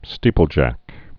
(stēpəl-jăk)